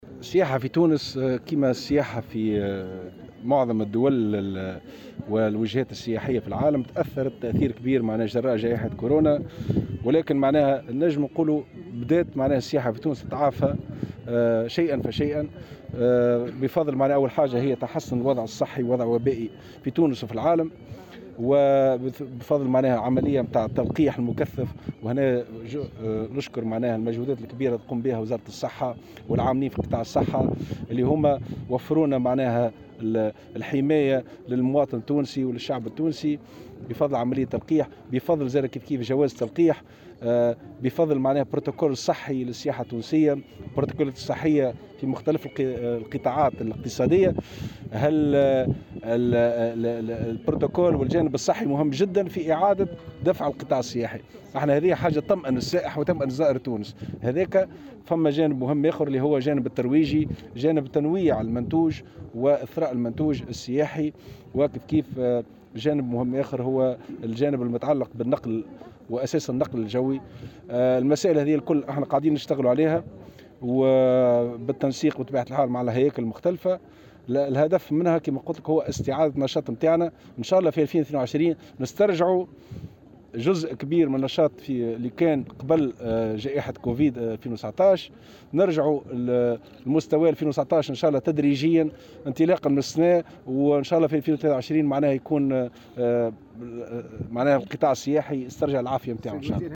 وأضاف في تصريح لمراسل "الجوهرة أف ام" على هامش زيارته لولاية تطاوين لإشرافه على انطلاق الايام الترويجية للدورة 41 للمهرجان الدولي للقصور الصحرواية ، أن الجانب الصحي والحرص على تطبيق البرتوكول الصحي عنصر مهم جدّا لإعادة دفع القطاع السياحي، إضافة الى العمل على تنويع المنتوج وتحسين جودة خدمات النقل الجوي.